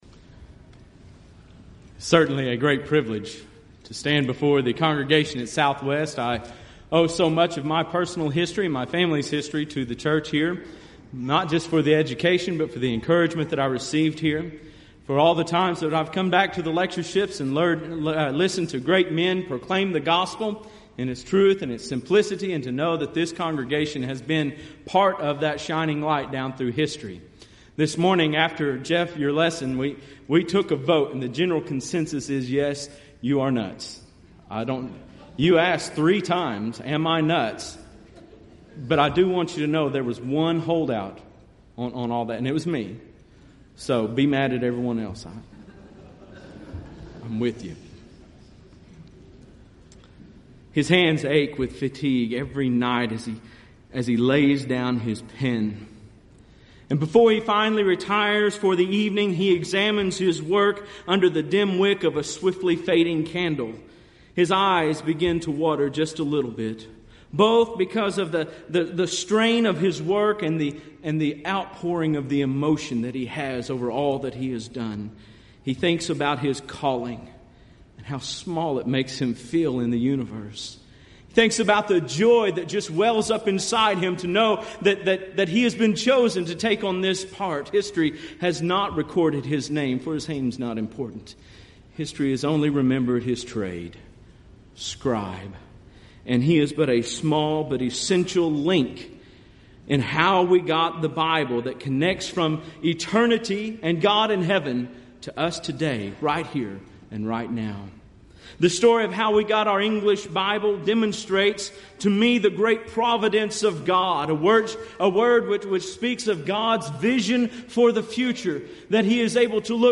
Event: 30th Annual Southwest Bible Lectures
If you would like to order audio or video copies of this lecture, please contact our office and reference asset: 2011Southwest13